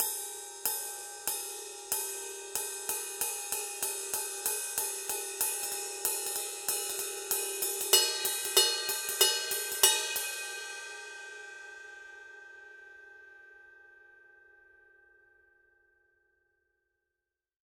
Type Ride
20_ride_pattern.mp3